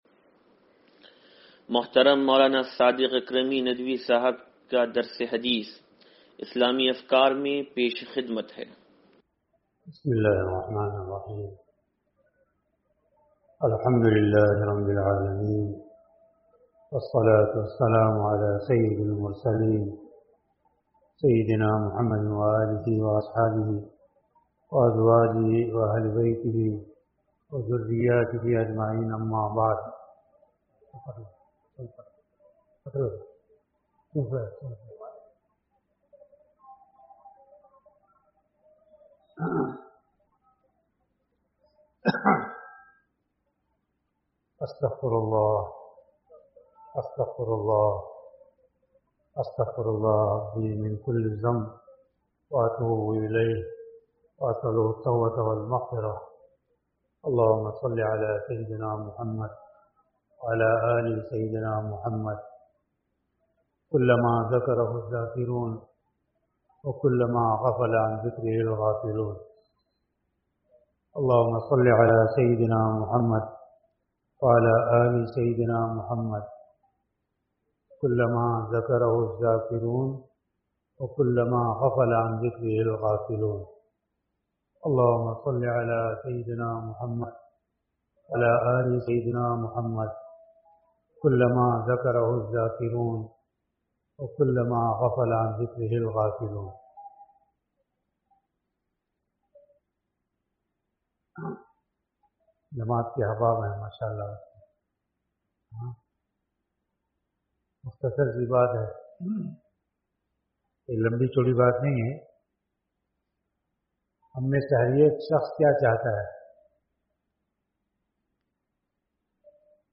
درس حدیث نمبر 0637